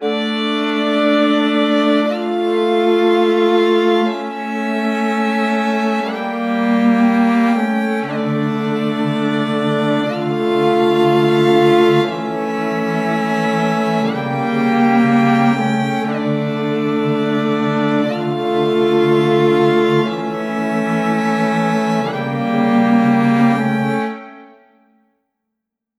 Categories: Melodies
20 High-Quality String Melodies Made Completely From Scratch.
Departed_120BPM_Dmin.wav